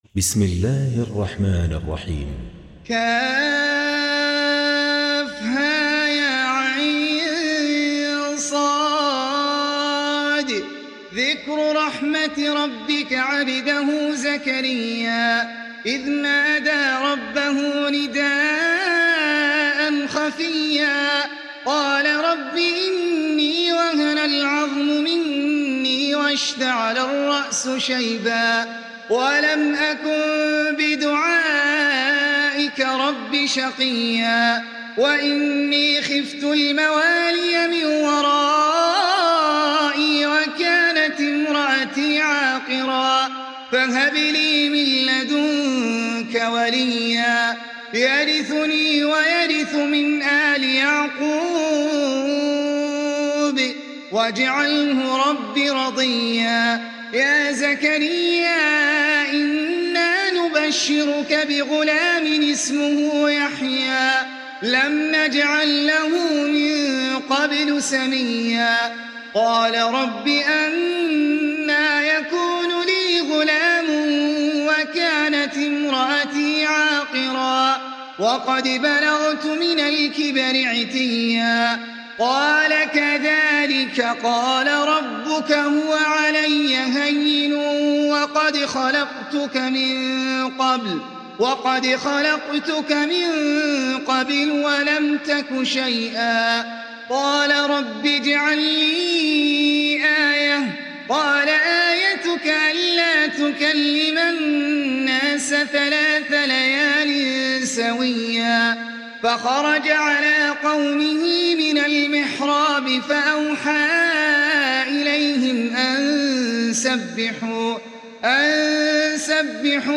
سورة مريم (الختمة مرتلة) - إنجليزي